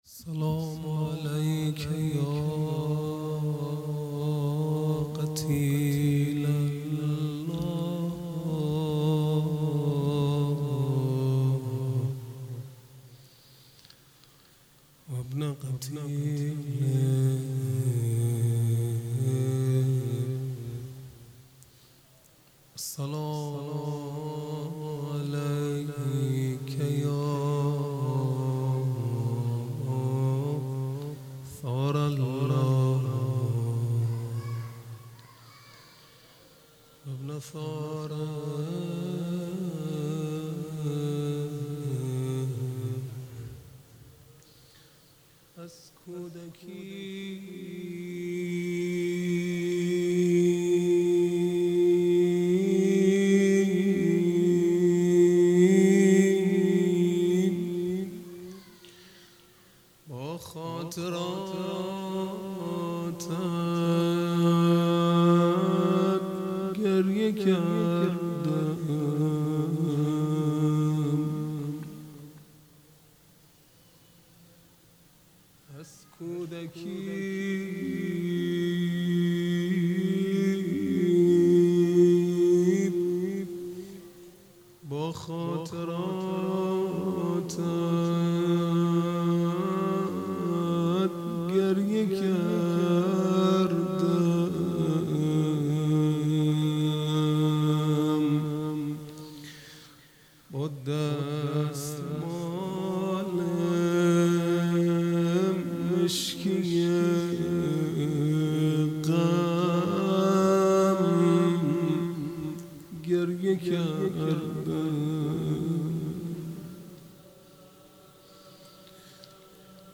روضه | از کودکی با خاطراتت گریه کردم